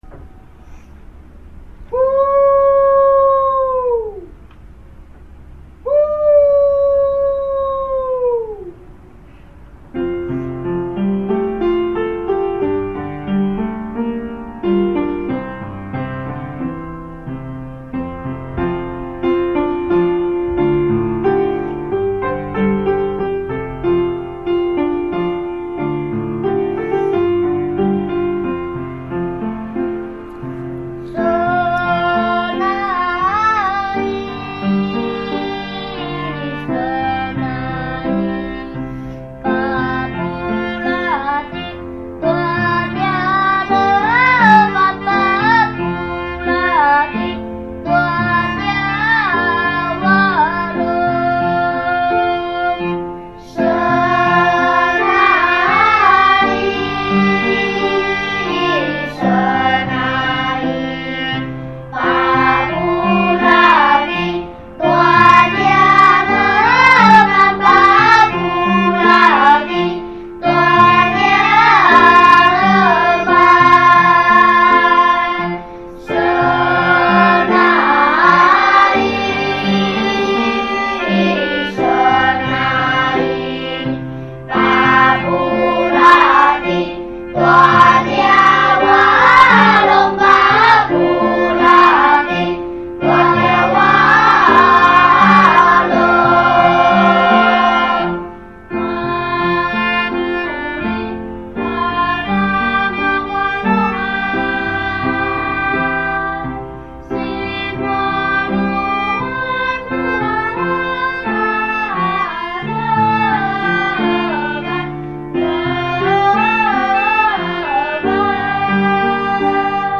屏山原民童聲合唱團